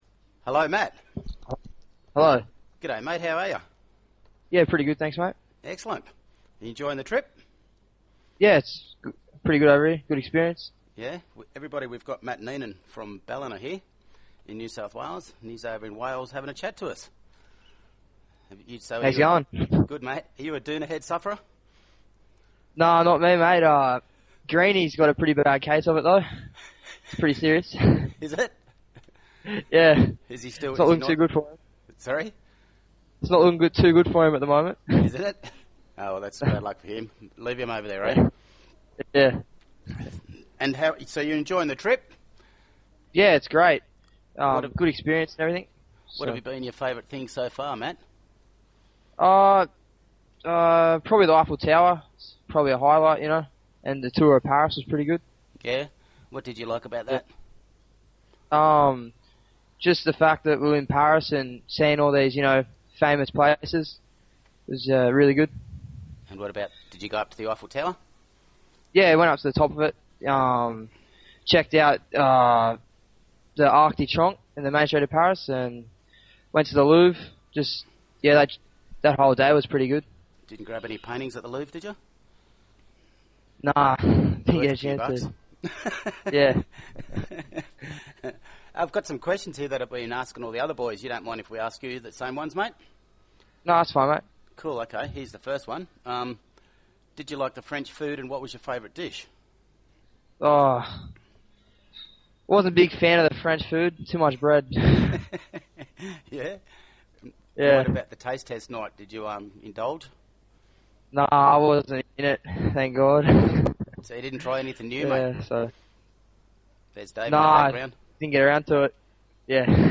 PLAYER INTERVIEWS